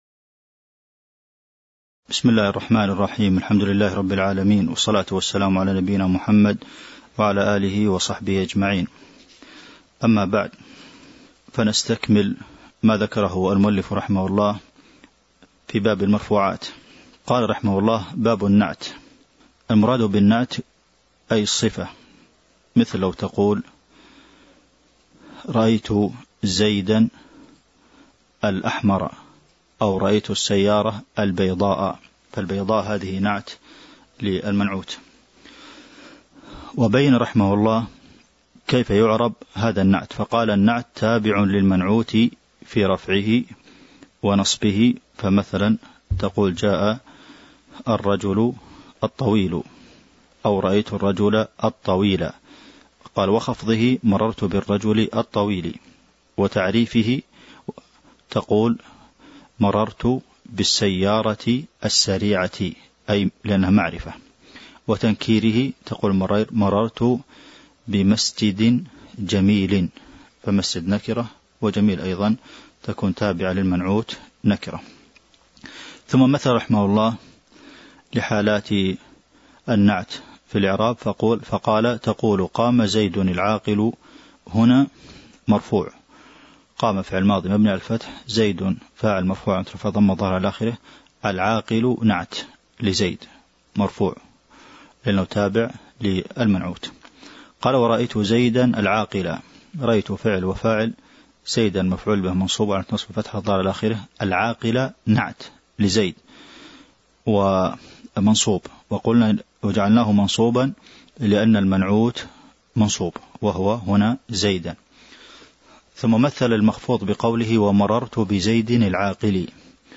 تاريخ النشر ٦ ذو القعدة ١٤٤٢ هـ المكان: المسجد النبوي الشيخ: فضيلة الشيخ د. عبدالمحسن بن محمد القاسم فضيلة الشيخ د. عبدالمحسن بن محمد القاسم باب النعت (04)